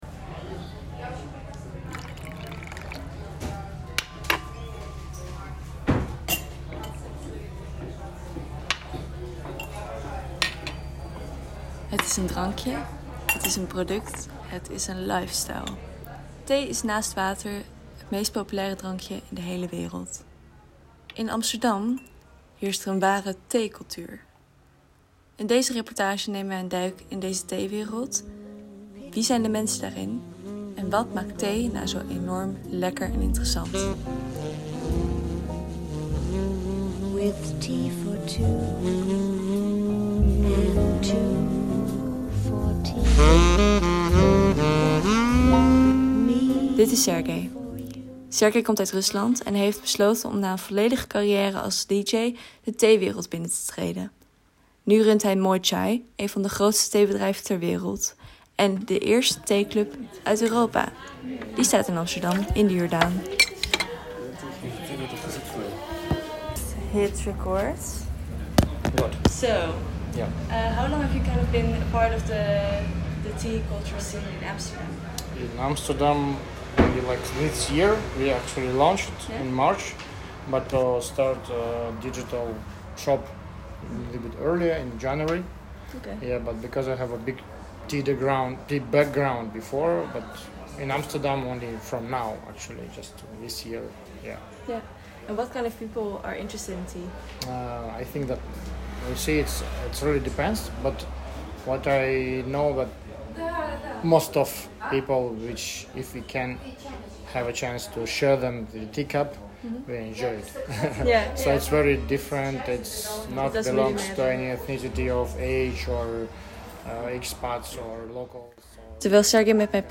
Hieronder is een audioreportage te beluisteren over de huidige thee “scene” in Amsterdam. Wie zijn de mensen die de cultuur rond deze stokoude traditie hip en relevant houden?